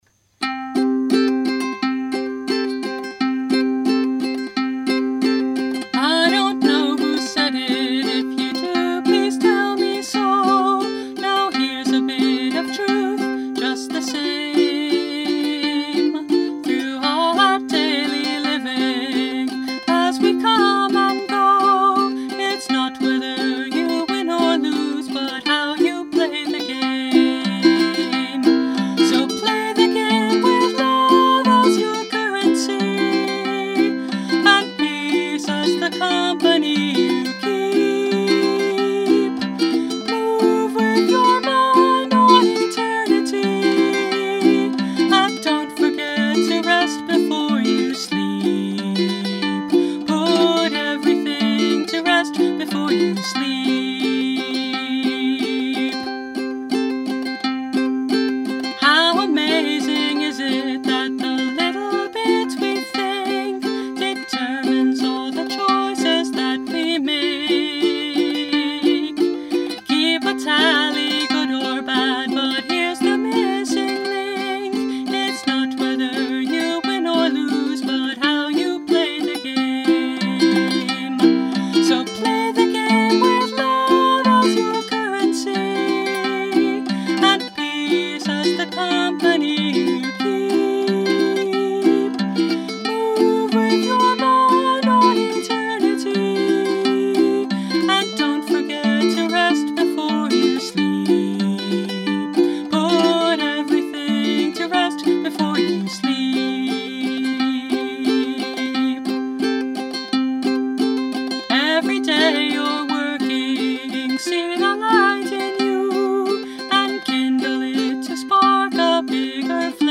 Instrument: Brio – Red Cedar Concert Ukulele
How You Play the Game – Ukulele Mp3.